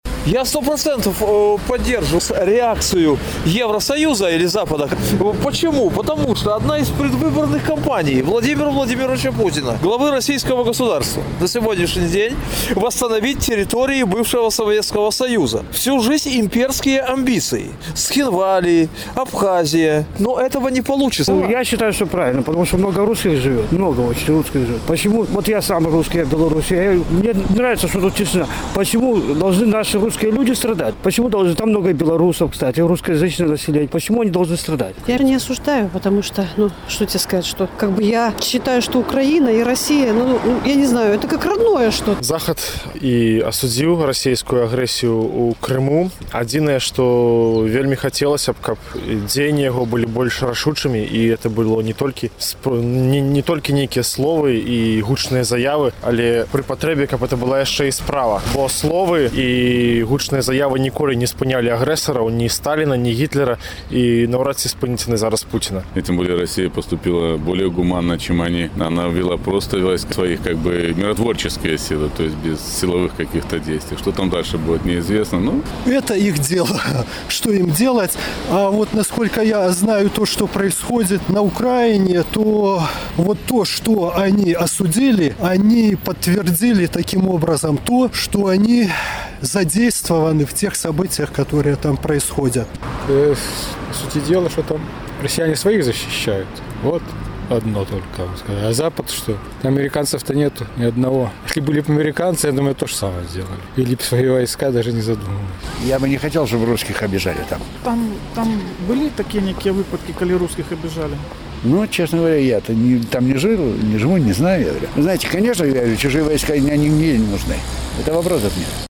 Як вы ацэньваеце рэакцыю Захаду на ўварваньне Расеі ў Крым? З такім пытаньнем наш карэспандэнт зьвяртаўся да гарадзенцаў.